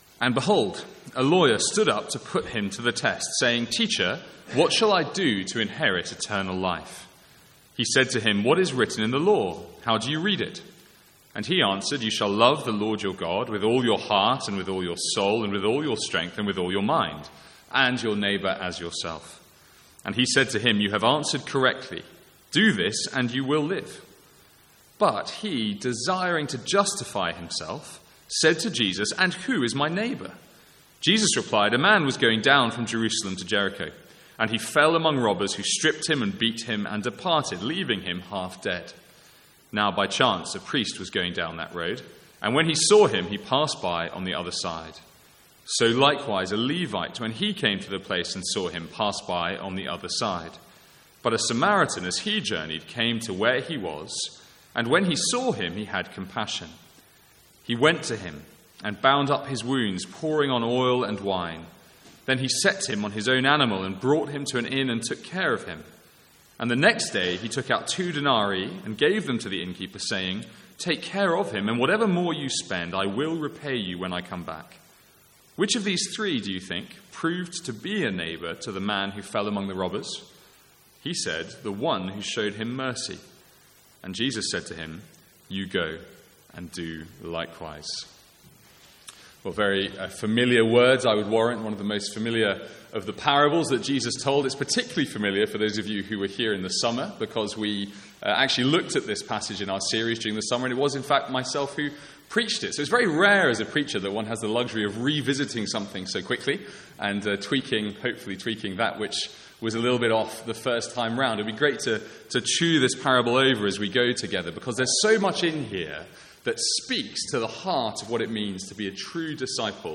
Sermons | St Andrews Free Church
From the Sunday evening series in Luke.